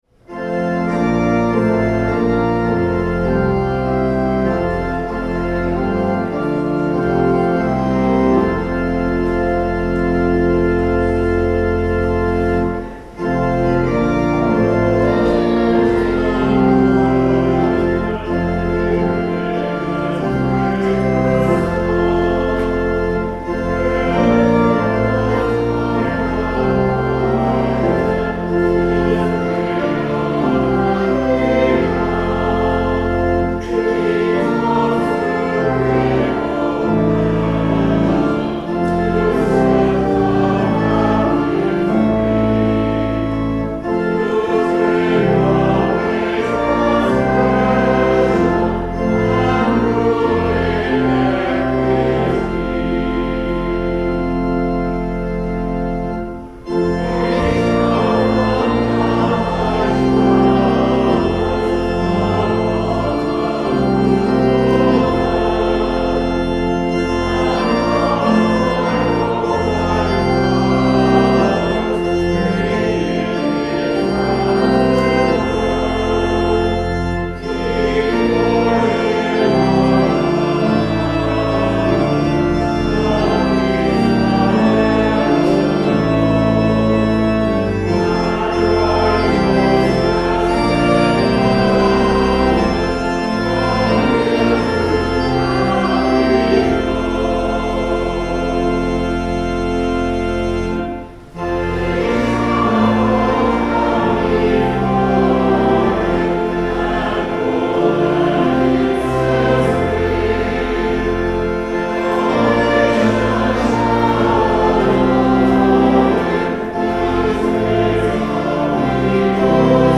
Hymn
Sermon
Anthem
The Lord’s Prayer (sung)